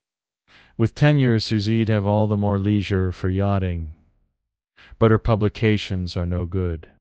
text-to-speech
The "Generate Speech with Fish Speech V1.5" action allows you to convert textual content into spoken words using advanced speech synthesis technology. This action is designed to solve the common issue of monotonous or robotic text-to-speech outputs, providing a more human-like voice experience.